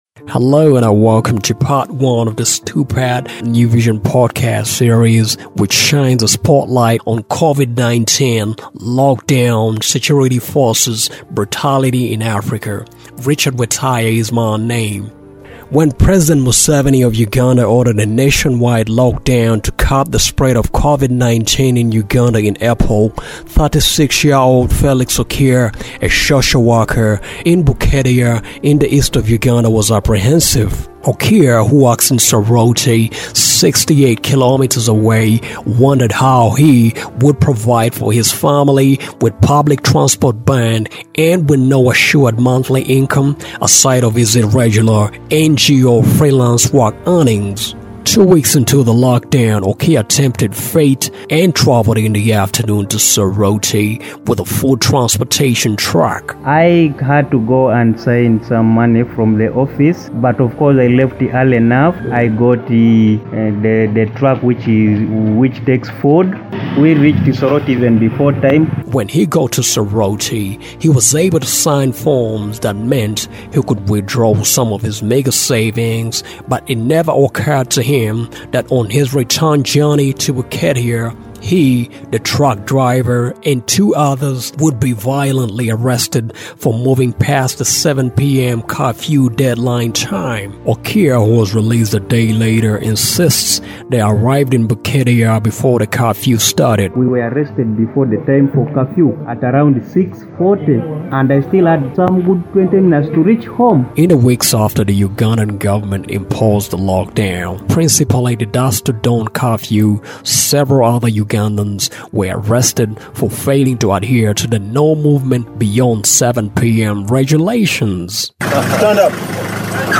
I sounded out seasoned Ugandan lawyers on the issue